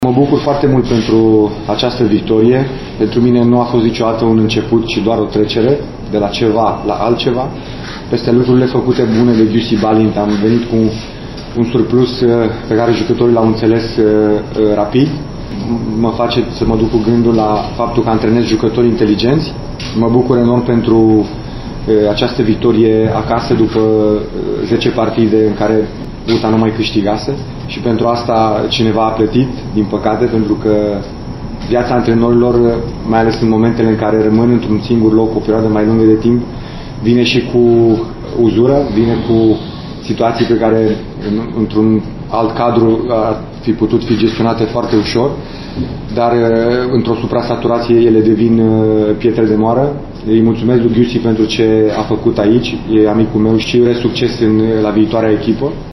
Noul tehnician al UTA-ei, Ionuț Badea, s-a bucurat pentru victorie și a mulțumit și predecesorului său, care i-a lăsat o echipă ”peste medie”.
Badea-bucurie-pt-victorie-multumiri-pt-Balint.mp3